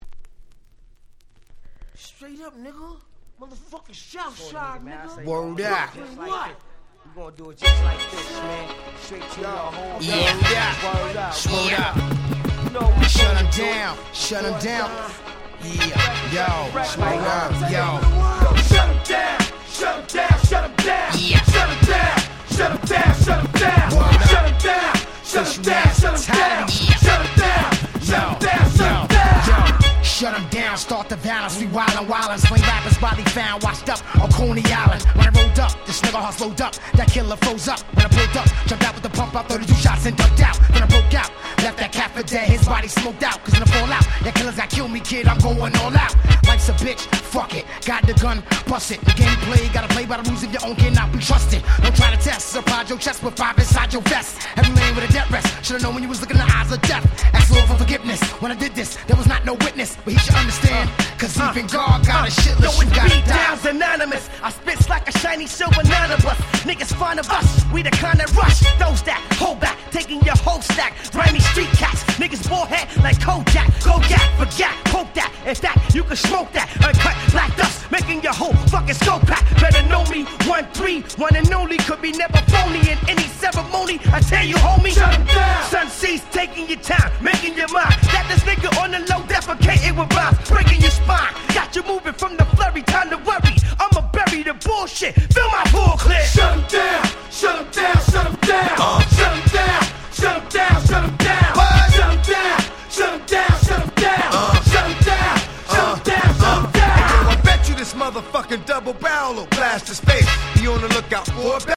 98' Smash Hit Hip Hop !!